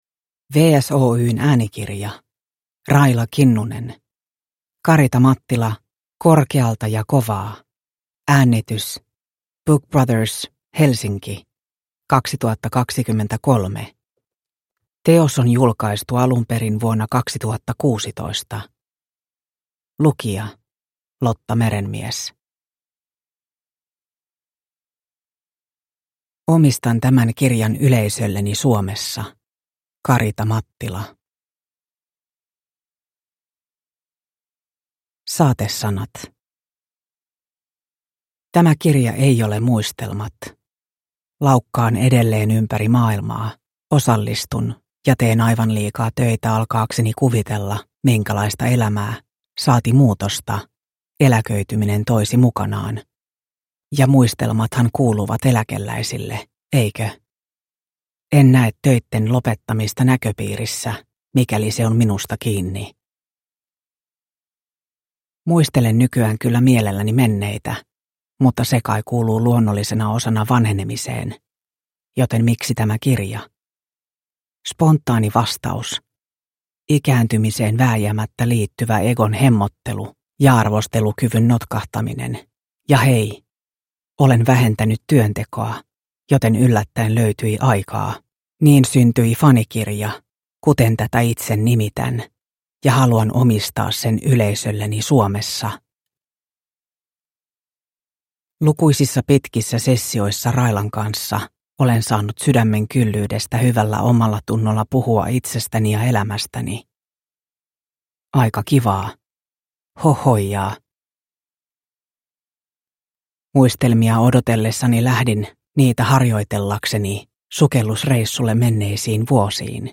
Karita Mattila - korkealta ja kovaa – Ljudbok – Laddas ner